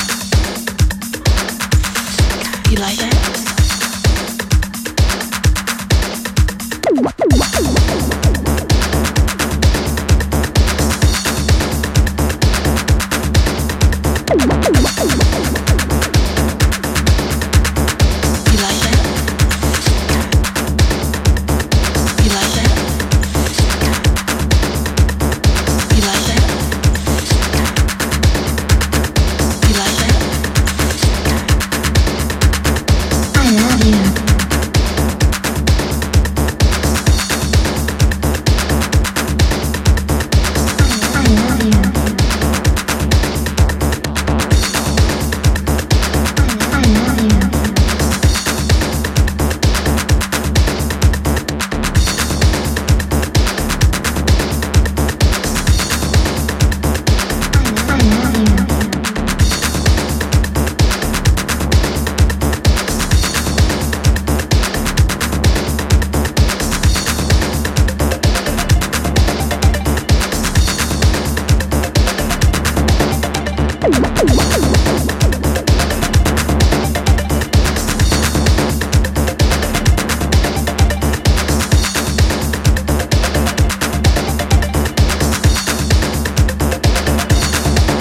ピークタイムを更に狂わせるアシッド・ハイエナジー